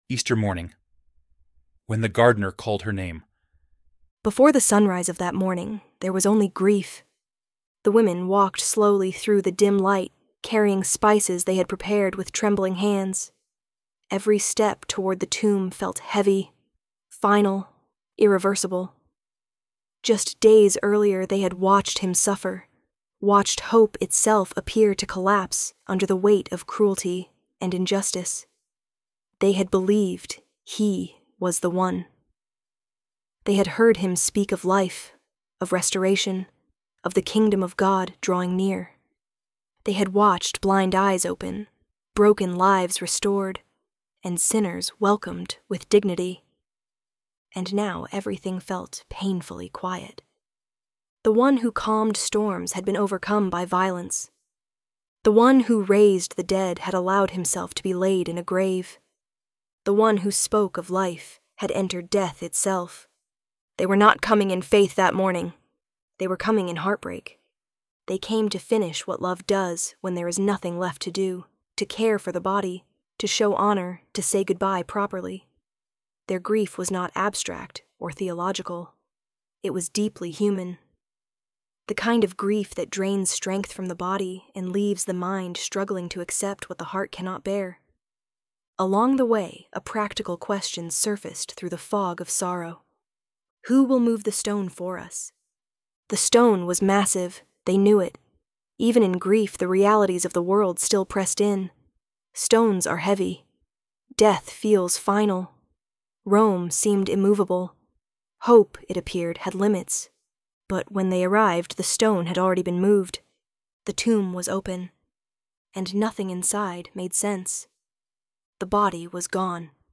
ElevenLabs_Easter_Morning.mp3